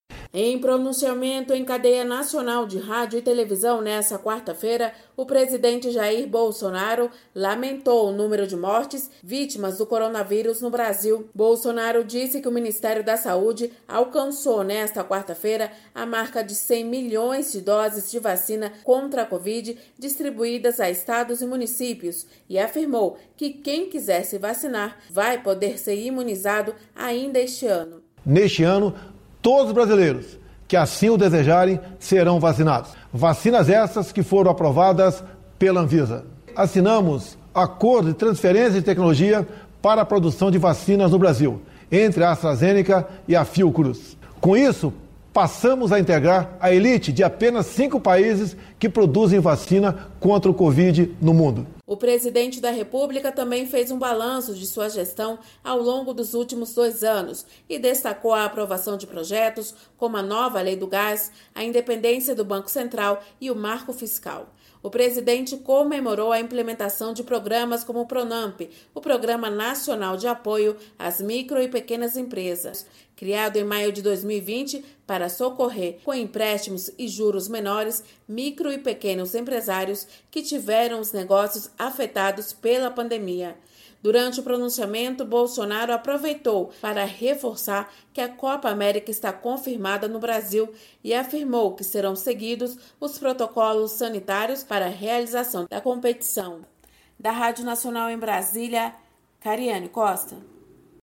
Pronunciamento Presidente